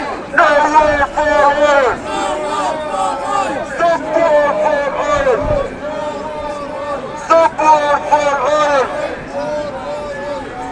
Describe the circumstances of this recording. Now passing a Libya demo...